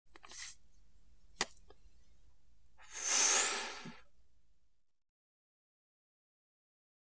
Cigarette Or Joint Inhale